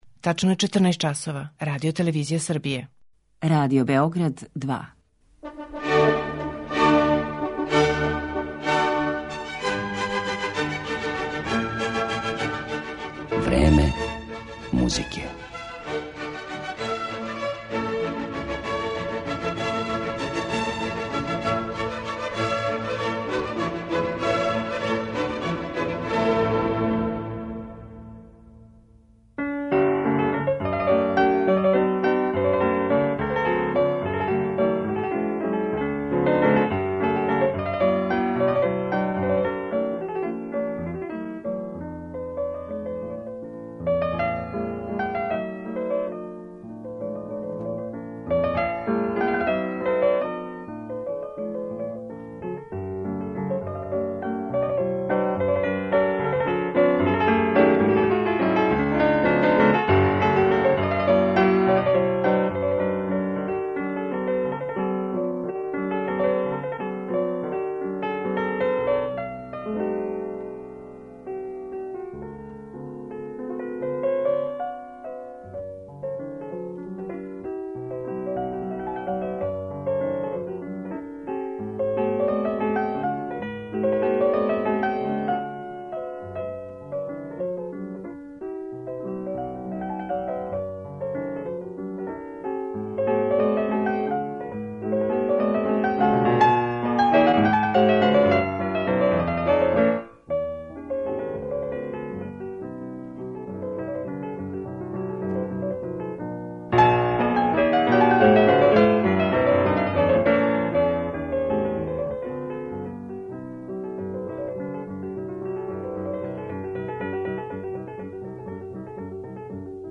Данашње 'Време музике' посвећено је Артуру Бенедетију Микеланђелију, једном од највећих пијаниста XX века и, свакако, једном од највећих чудака међу музичарима.
Сви су били опчињени његовим особеним тоном и још особенијом филозофијом музике, оригиналним тумачењима музичких дела.